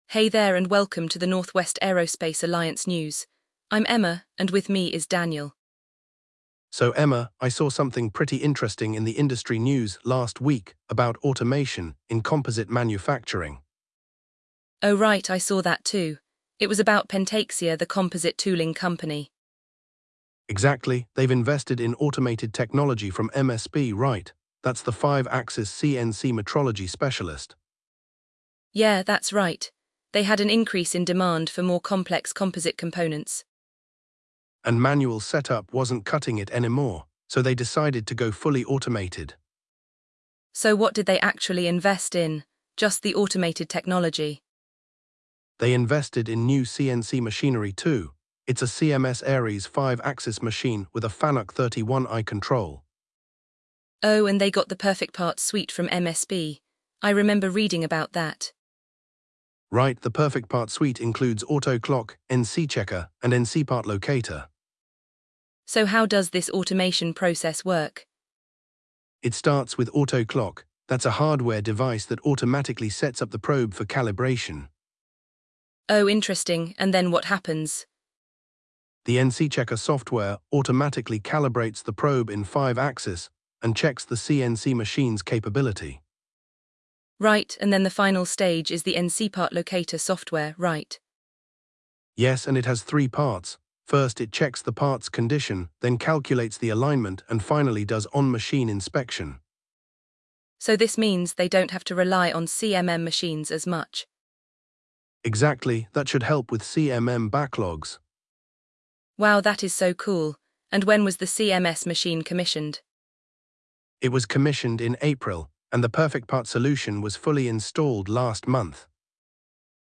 They delve into the company's investment in new CNC machinery from MSP, including the CMS Ares 5-axis machine with Fanuc 31i control, and the PerfectPart suite. The conversation covers the benefits of automation, such as improved accuracy, reduced reliance on CMM machines, and enhanced sustainability.